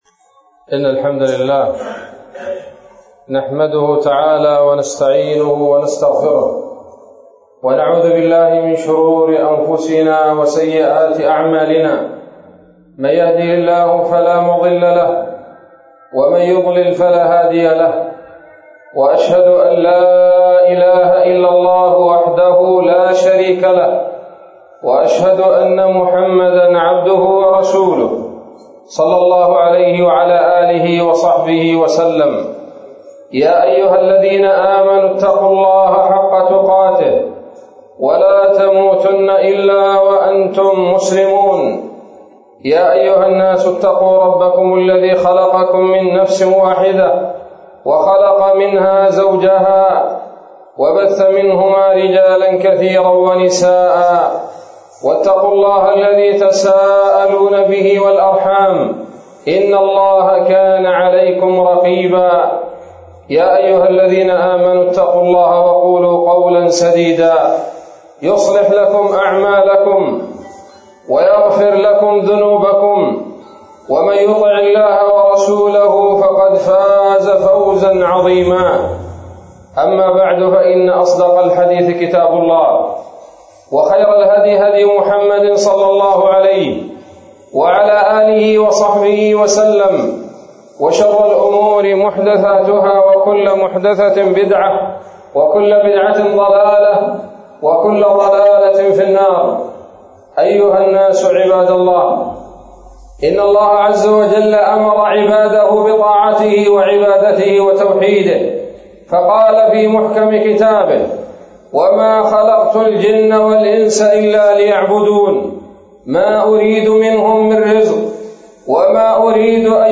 خطبة جمعة بعنوان: (( رفعة العلم )) 22 ذو القعدة 1442 هـ